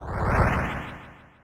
snd_ghostappear.ogg